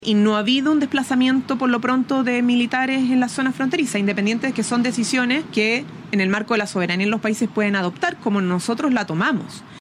La vocera de Gobierno, Camila Vallejo, reforzó ese diagnóstico y añadió un matiz importante: lo de Perú sigue siendo un anuncio, no una ejecución en su frontera con Chile.